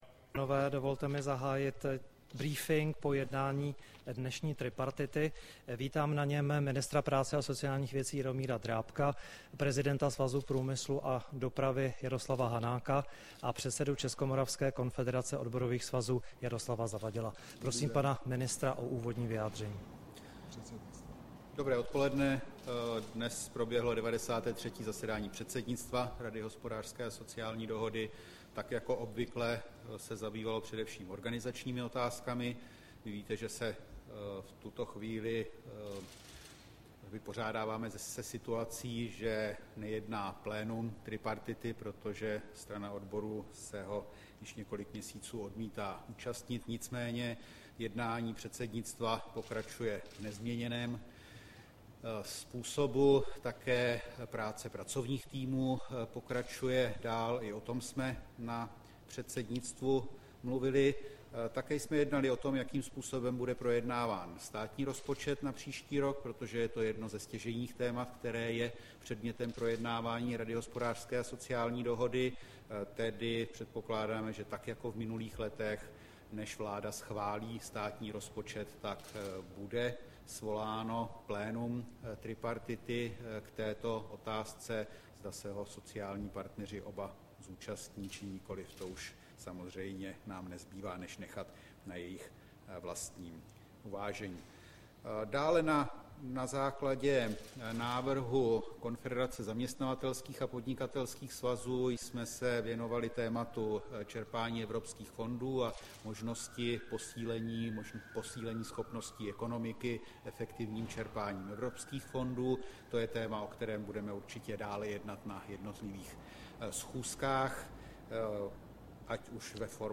Tisková konference po jednání tripartity, 26. července 2012